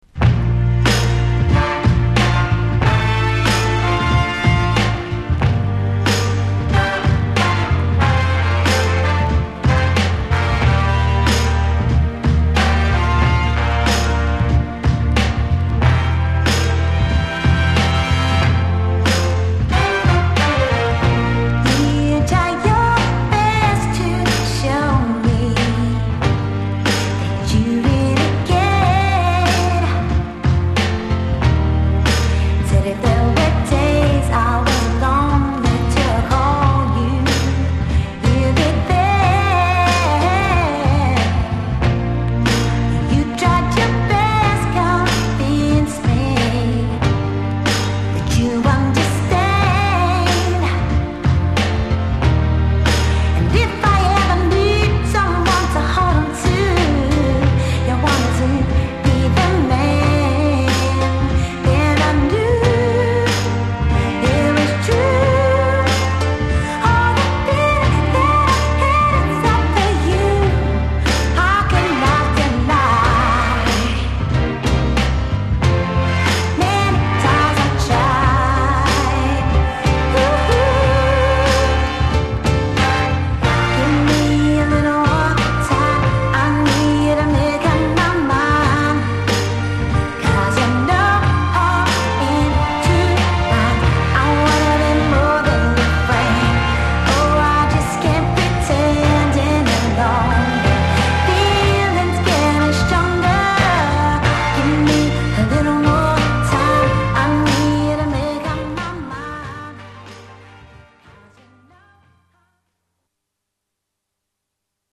Genre: #R&B
Sub Genre: #1990s